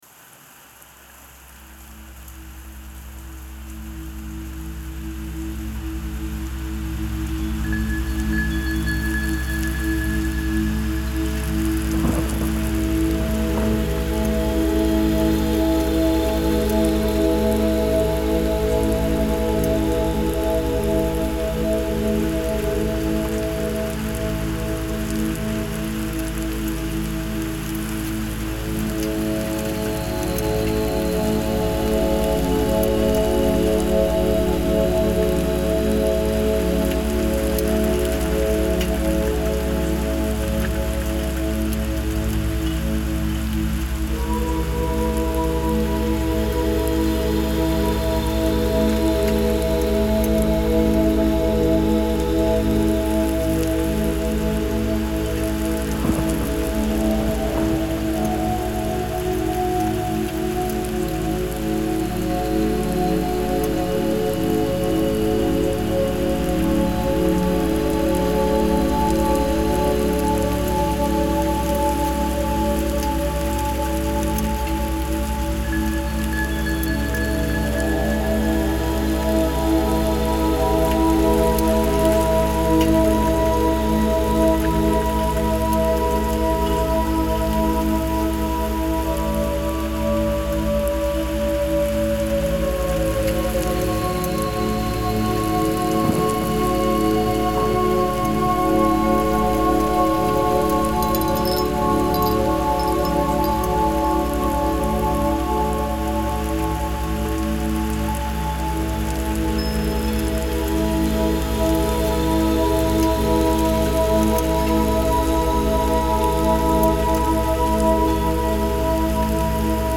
Relaxation music